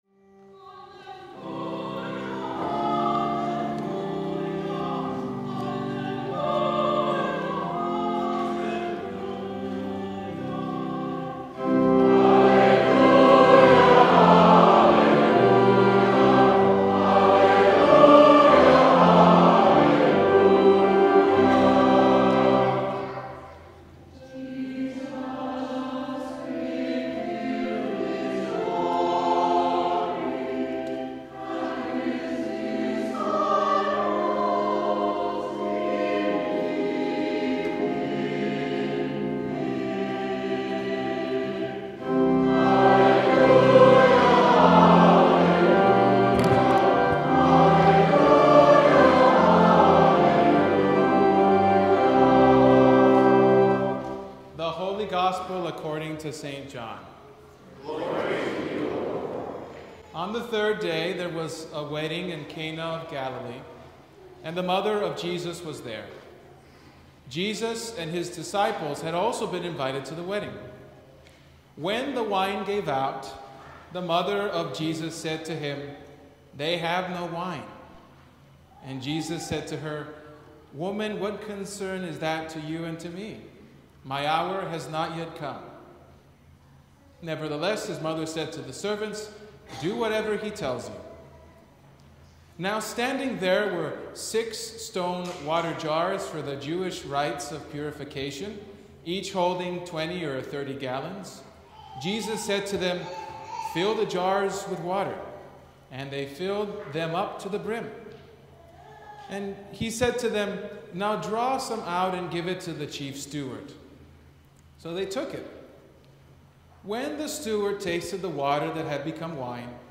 Sermon from the Sunday After Epiphany | MLK Commemoration